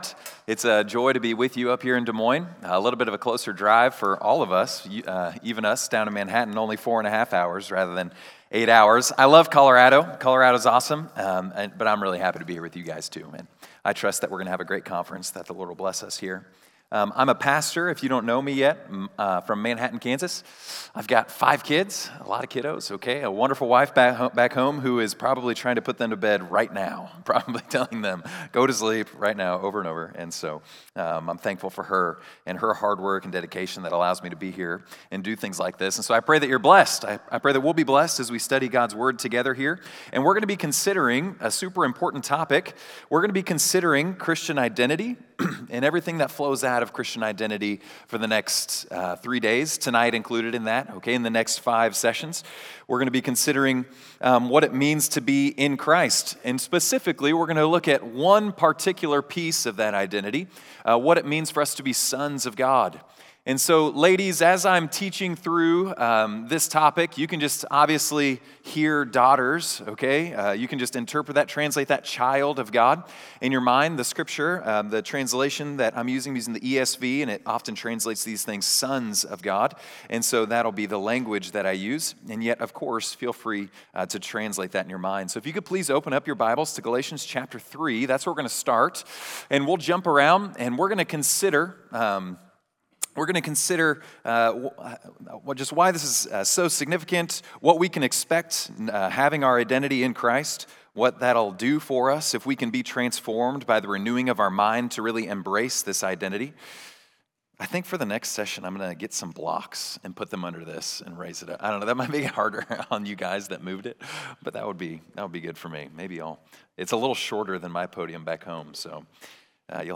What It Means to Be "In Christ" | Winter Retreat 2025 | In Christ: Being before Doing - Campus Fellowship